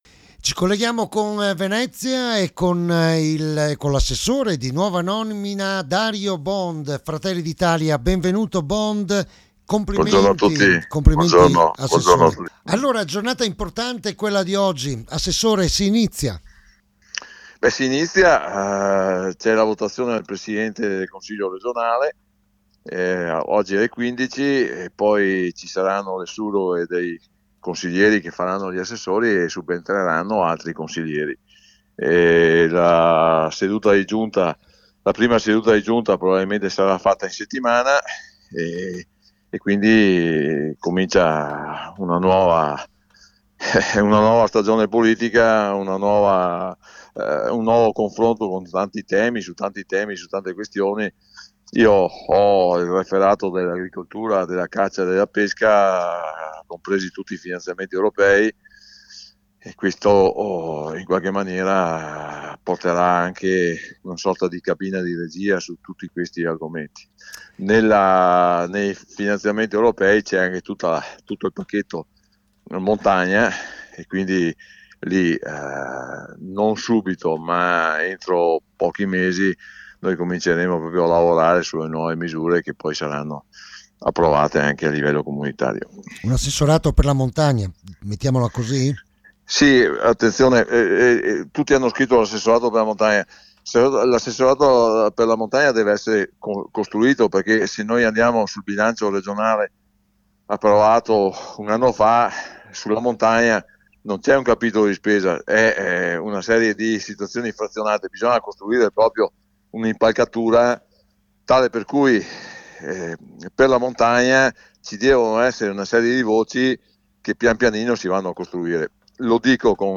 L’ASSESSORE REGIONALE DARIO BOND A RADIO PIU’
OSPITE: Dario Bond